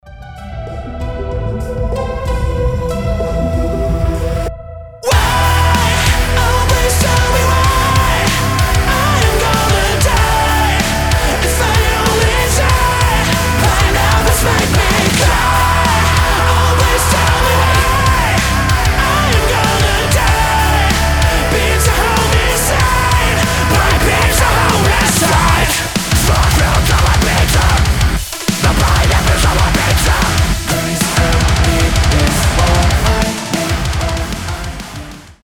• Качество: 320, Stereo
мощные
Metalcore
веселые
Growling